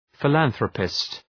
Προφορά
{fı’lænɵrəpıst}
philanthropist.mp3